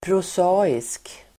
Ladda ner uttalet
Uttal: [pros'a:isk]